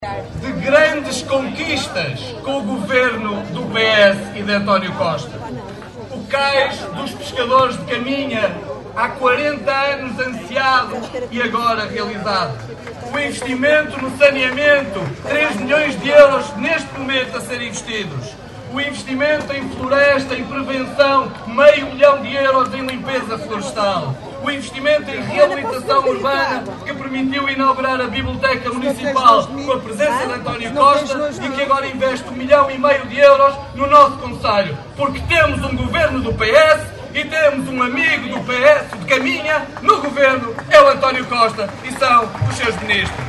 Os socialistas rumaram a norte no passado sábado (25 de agosto) para a habitual “rentrée” política que este ano teve lugar no Parque Municipal em Caminha.
Miguel Alves, presidente da Federação do PS de Viana, foi o primeiro a usar da palavra para lembrar as “grandes conquistas” para Caminha e para o Alto Minho com o Governo do PS.